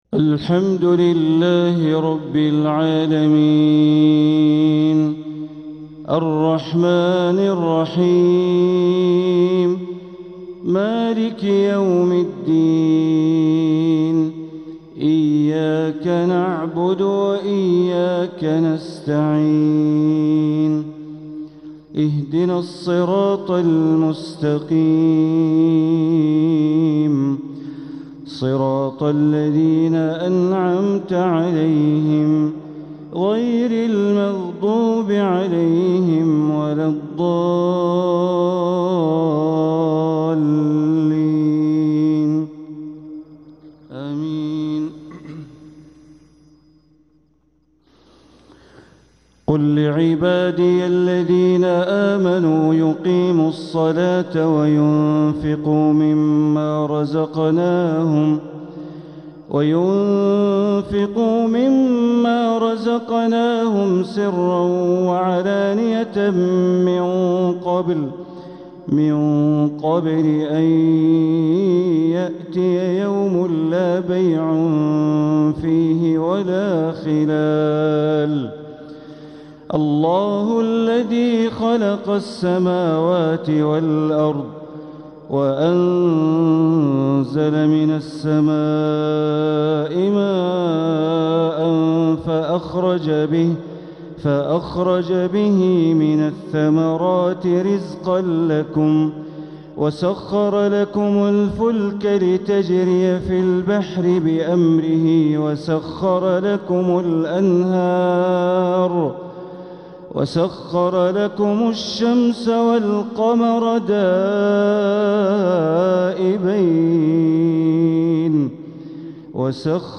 تلاوة من سورة إبراهيم ٣١-٥٢ | فجر الثلاثاء ١٧ربيع الأول ١٤٤٧ > 1447هـ > الفروض - تلاوات بندر بليلة